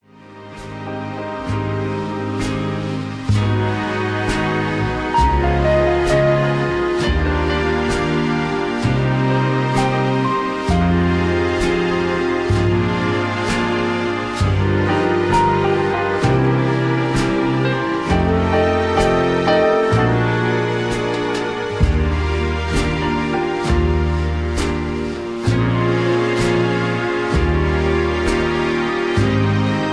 (Version-1, Key-Eb) Karaoke MP3 Backing Tracks
Just Plain & Simply "GREAT MUSIC" (No Lyrics).